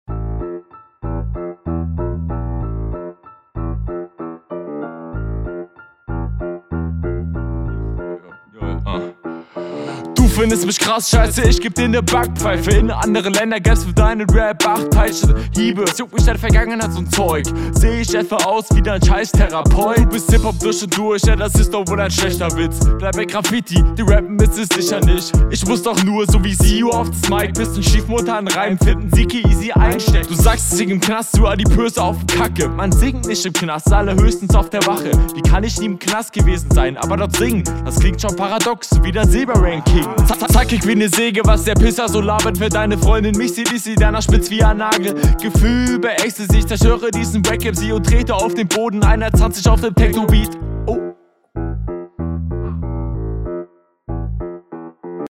hörgenuss ist etwas runder. punches fehlen nen bisschen. stimme geht mehr nach vorn iwie. wache …
Der Flow ist besser, aber hätte viel besser sein können.